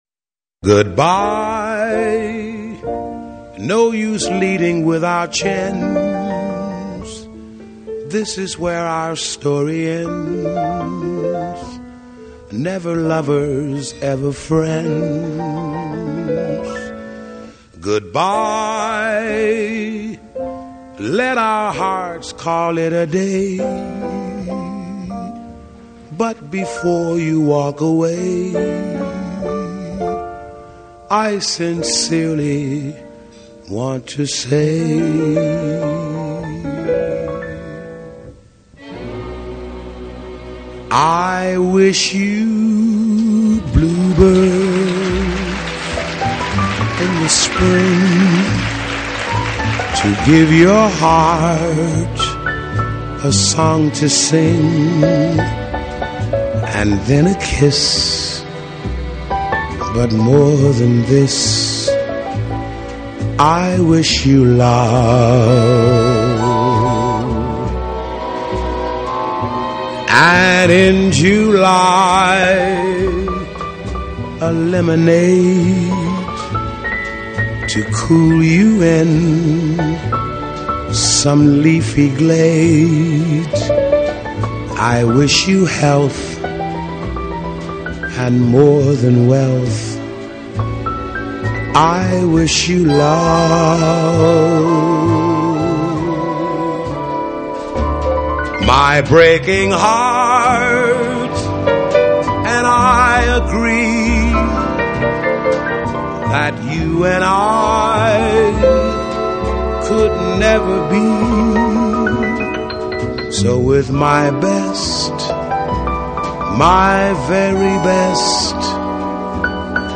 好听的印度音乐